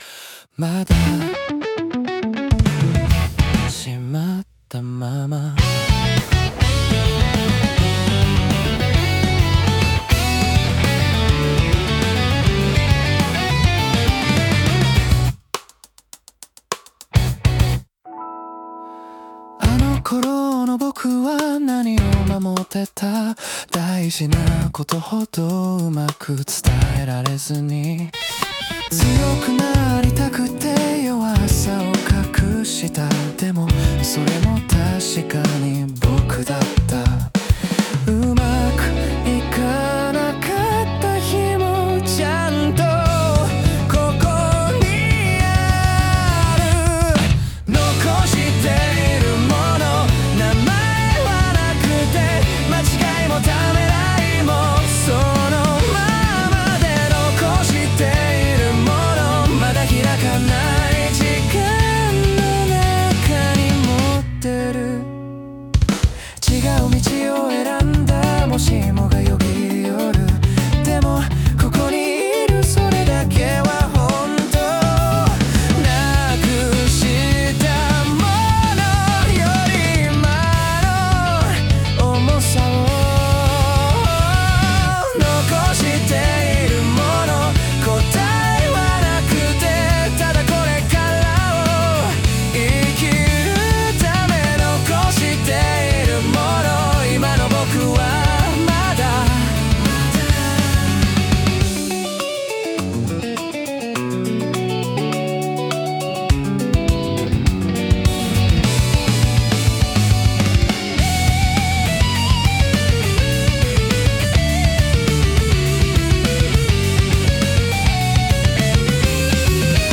男性ボーカル
イメージ：シティPOP,男性ボーカル,かっこいい,切ない,エモい